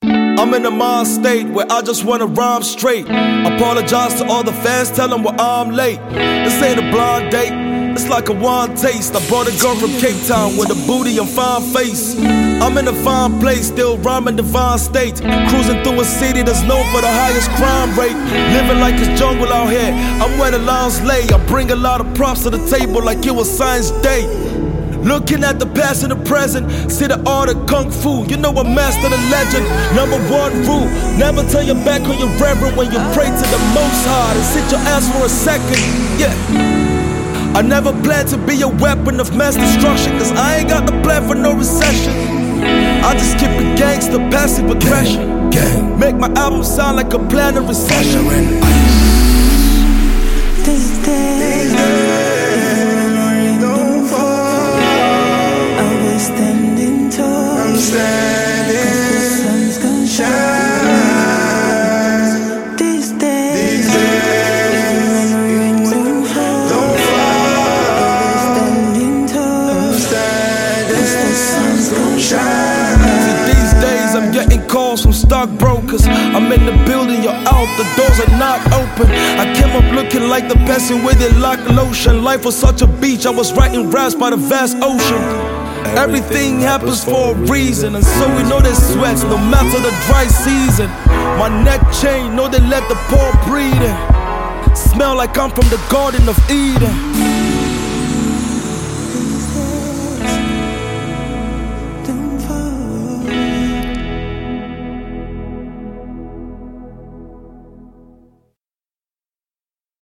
a great Nigerian rapper and artist